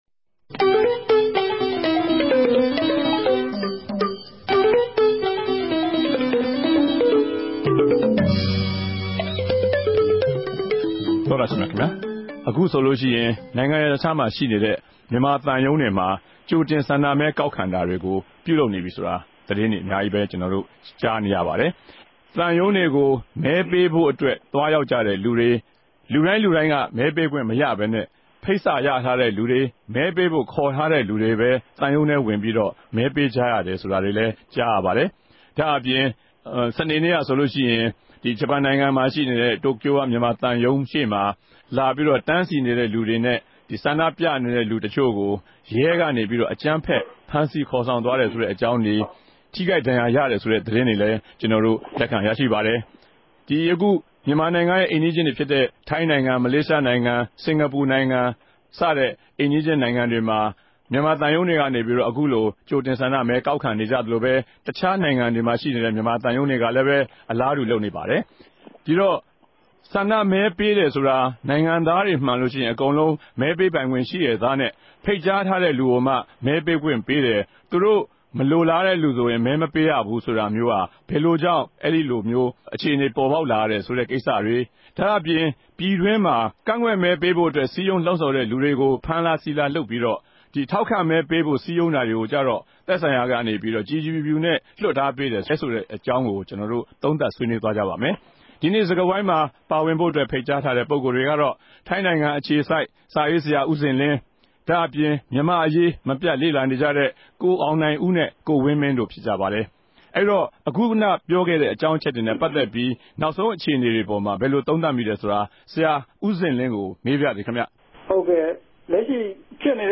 တနဂဿေိံြ ဆြေးေိံြးပြဲ စကားဝိုင်း။